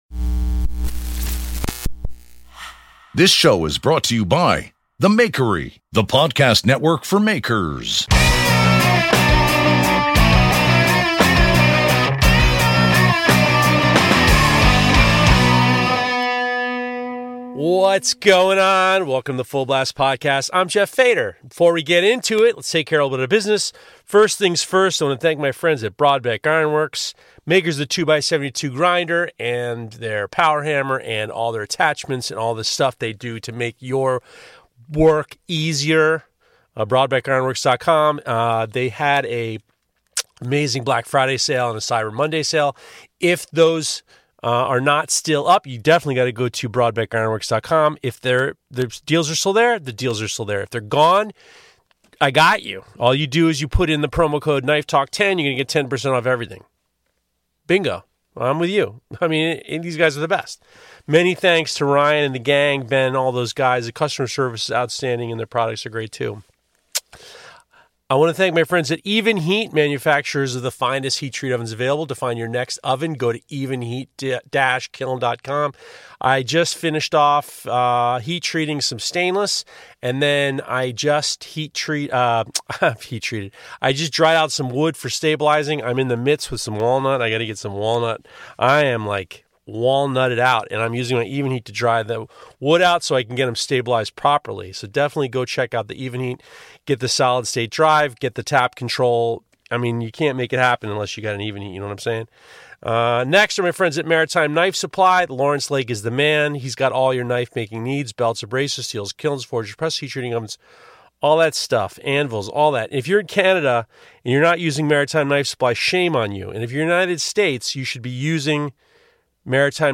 This Week we had a few technical issues and I had to abandon an interview and do an emergency Solo show.
It was so much fun and it sounded way better than I thought. We talked about the holidays, told embarrassing stories and had some laughs.